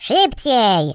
share/hedgewars/Data/Sounds/voices/Russian_pl/Hurry.ogg
Hurry.ogg